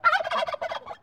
animalia_turkey.ogg